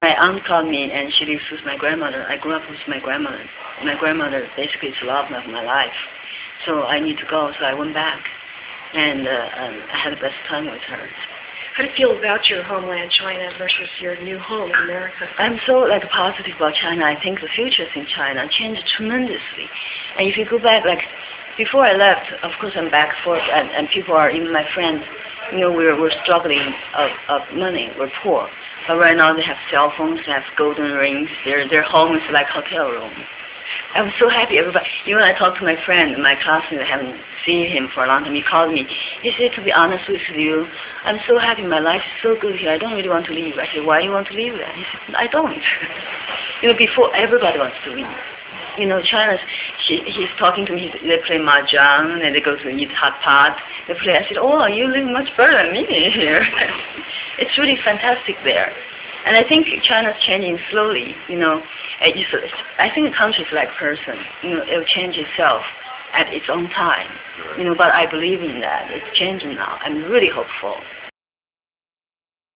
Bai_Ling_Interview2.rm